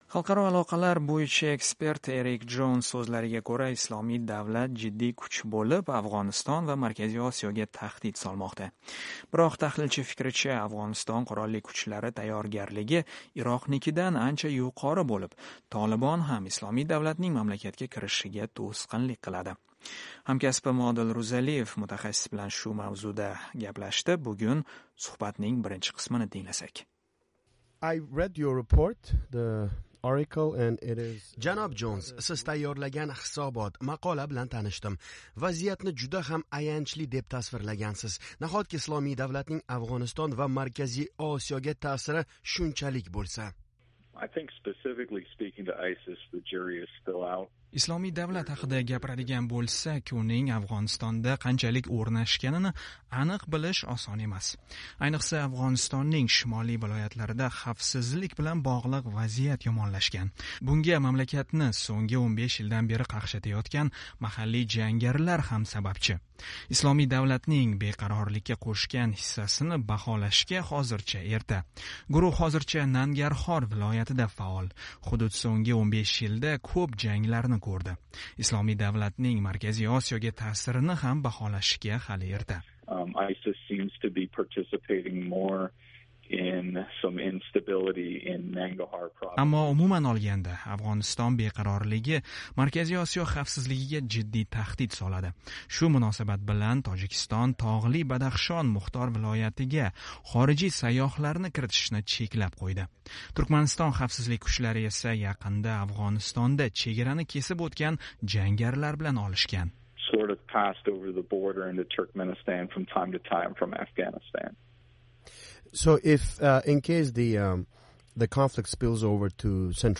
Amerikalik tahlilchi bilan "Islomiy davlat"ning Markaziy Osiyoga tahdidi haqida intervyu (o'zbeckha)